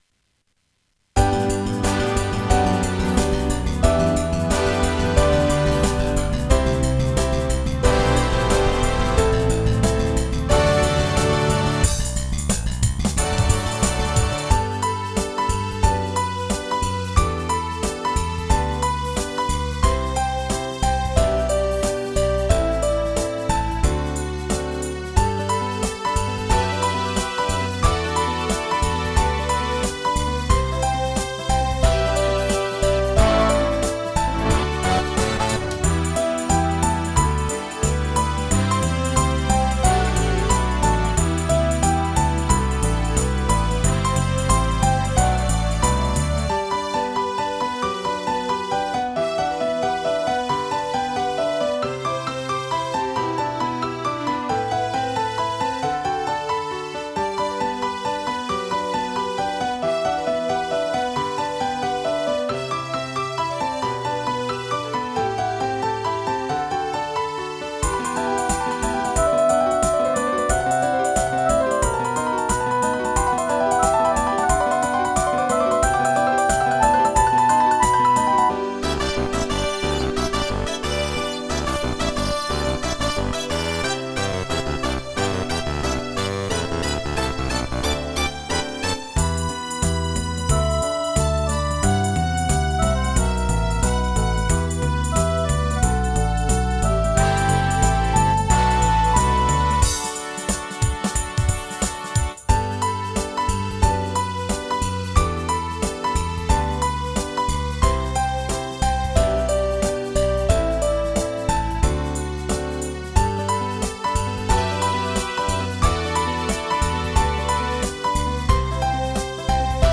４．個人の作った曲（Desktop Music)　の紹介